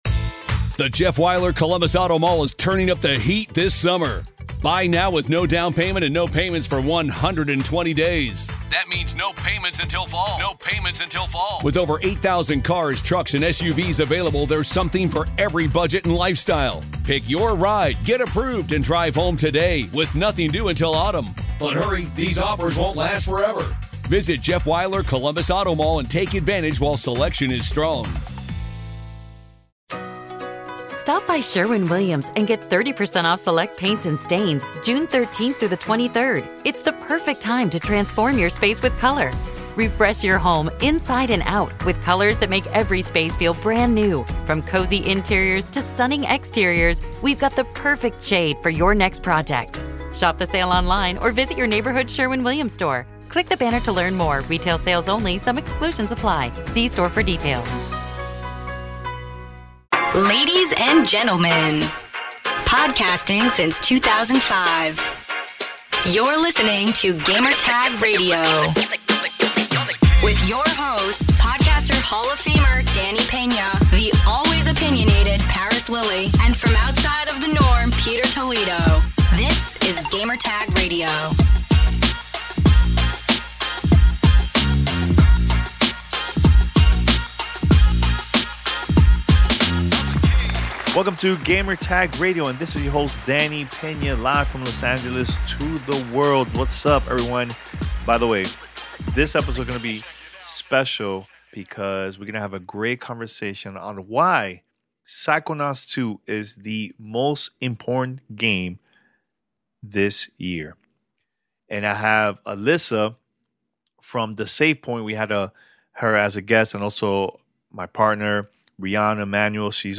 Roundtable discussion about why Psychonauts 2 is the most important game this year.